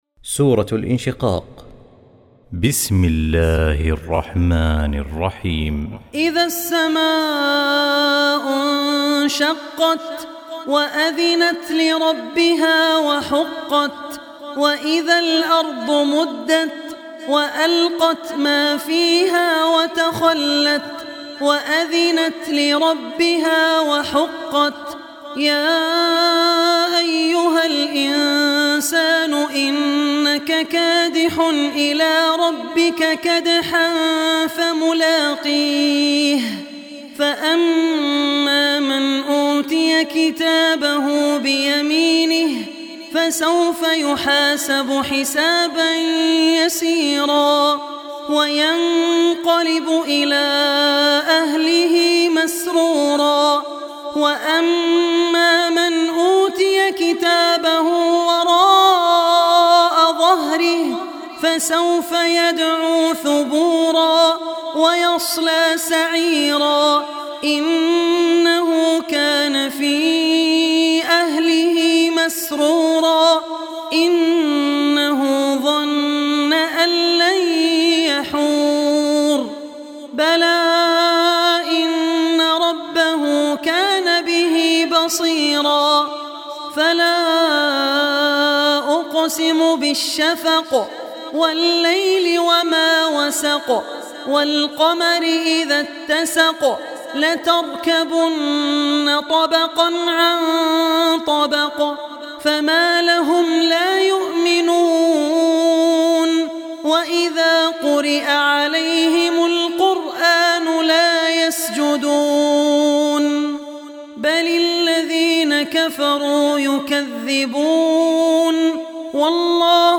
Surah Inshiqaq Recitation by Abdur Rehman Al Ossi
Surah Inshiqaq, listen online mp3 tilawat / recitation in the voice of Sheikh Abdur Rehman Al Ossi.
Surah Inshiqaq free audio recitation.